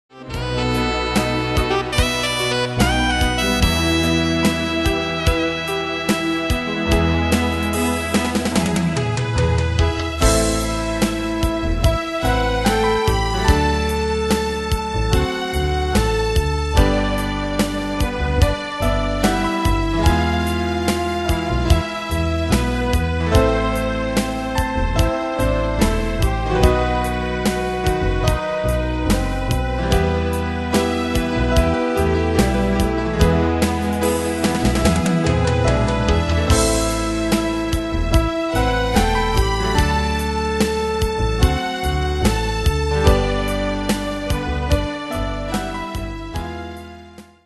Style: PopAnglo Ane/Year: 1982 Tempo: 73 Durée/Time: 4.55
Danse/Dance: Ballade Cat Id.
Pro Backing Tracks